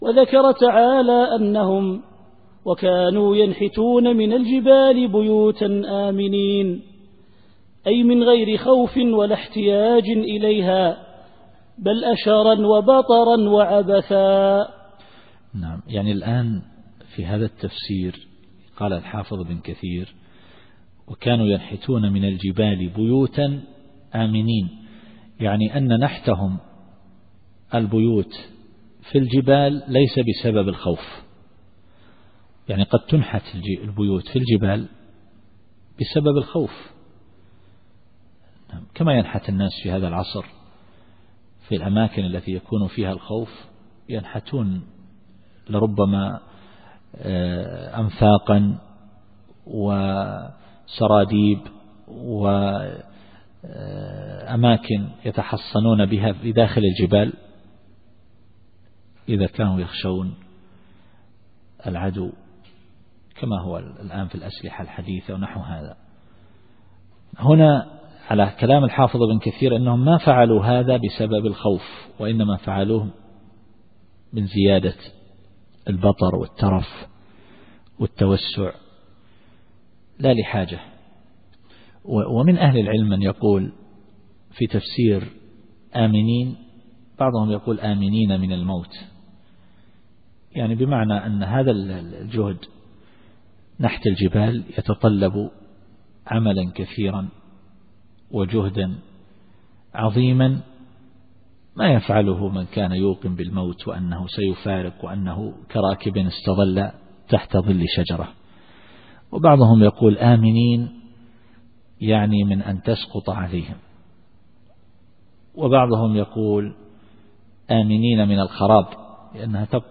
التفسير الصوتي [الحجر / 82]